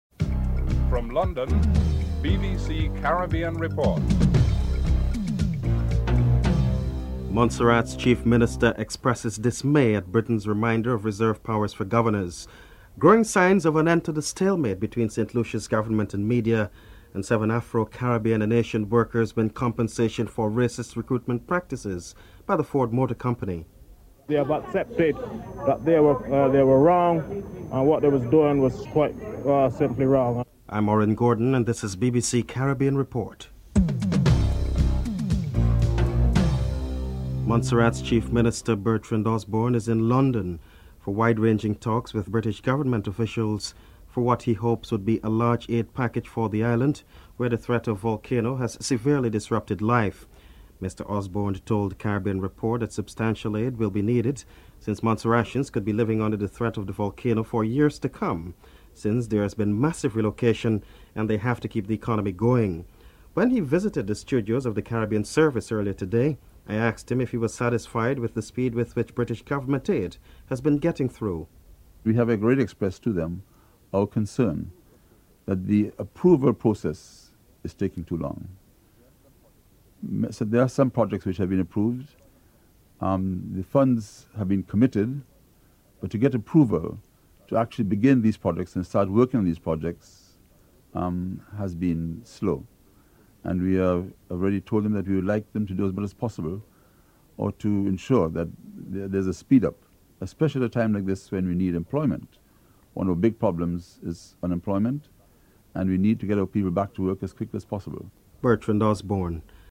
1. Headlines (00:00-00:36)
Bertrand Osborne, Montserrat's Chief Minister is interviewed (00:37-04:21)